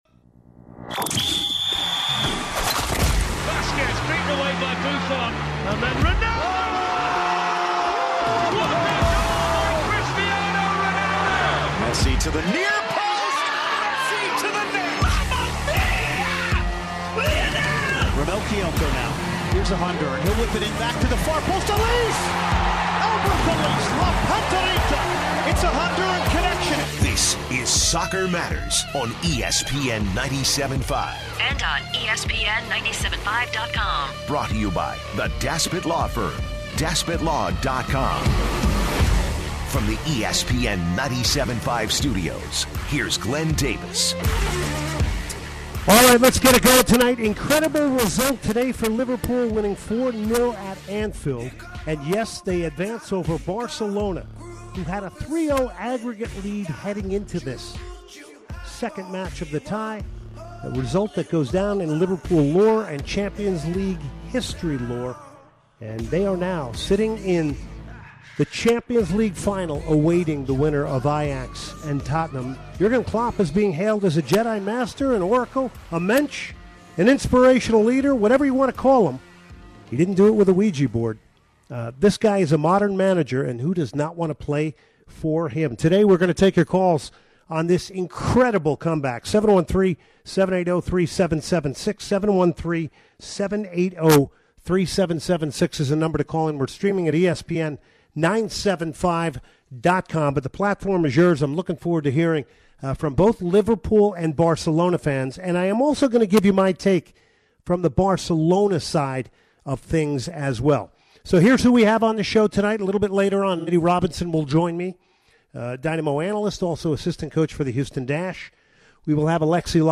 He takes calls from Liverpool fans and how they think this could be the best comeback they have ever had.